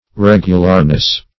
Regularness \Reg"u*lar*ness\, n.